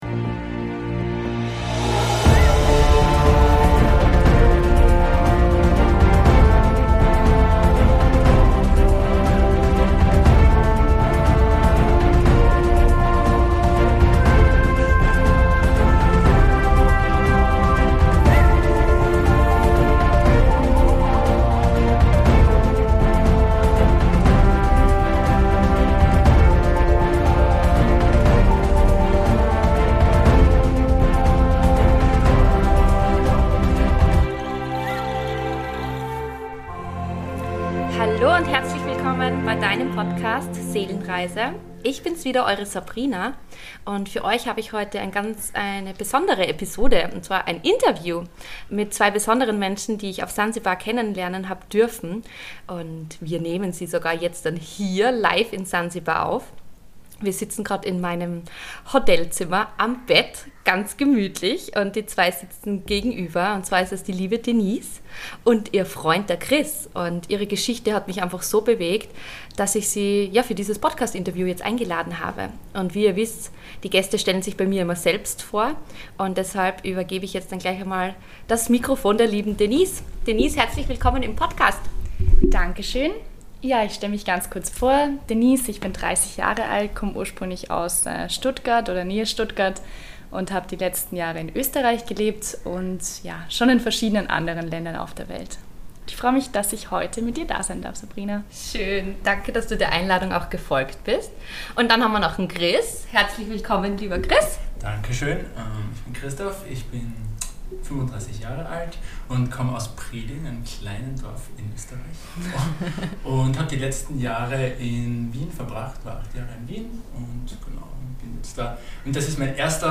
Ihr Wundervollen, heute habe ich ein wunderschönes Interview für euch.